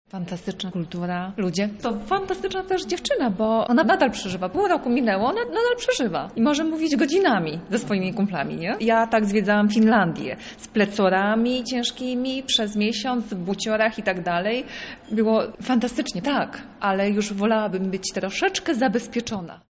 O swoich wrażeniach mówi jedna z uczestniczek spotkania.